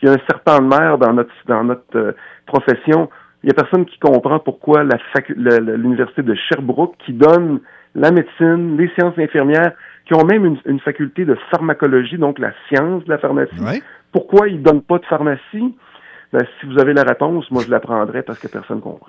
En entrevue plus tôt ce matin, celui qui pratique son métier du côté de Trois-Pistoles a tenu à souligner le caractère vocationnel de ses collègues. Il aimerait voir plus de pharmaciens être formés à l’échelle québécoise, se questionnant sur la volonté réelle du gouvernement et des facultés universitaires à ouvrir de nouvelles opportunités de formation.